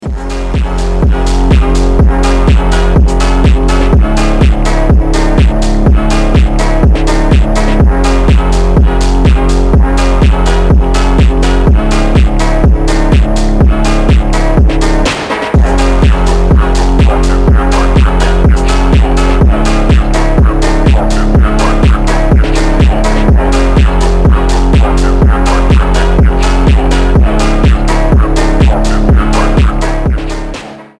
• Качество: 320, Stereo
мужской голос
Electronic
мощные басы
качающие
быстрые
фонк
Стиль: phonk